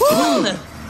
SUDDEN GASPING.wav
Original creative-commons licensed sounds for DJ's and music producers, recorded with high quality studio microphones.
sudden_gasping_rmf.mp3